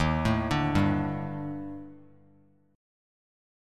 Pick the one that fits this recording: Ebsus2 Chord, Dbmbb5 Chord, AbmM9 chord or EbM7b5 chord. EbM7b5 chord